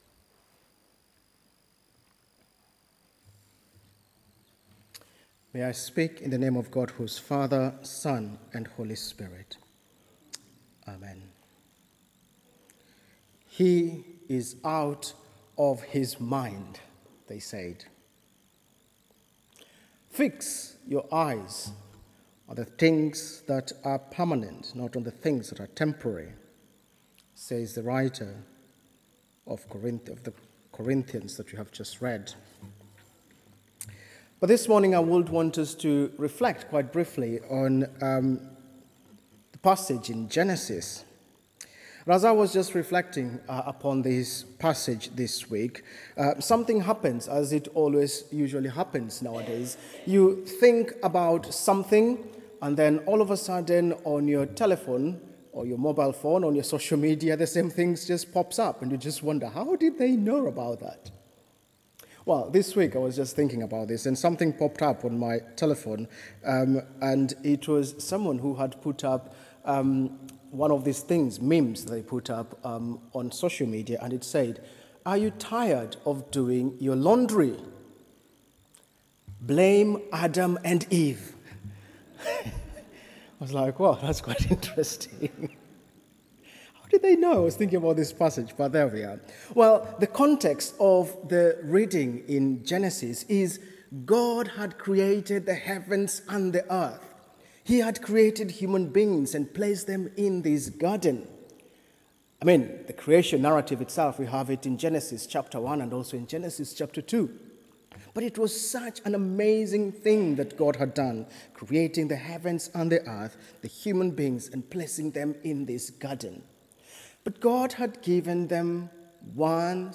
Sermon: Out of his mind?